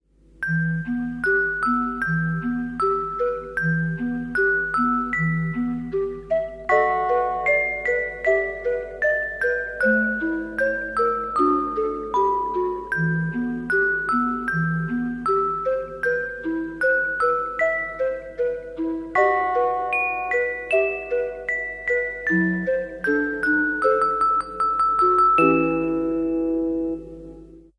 E minor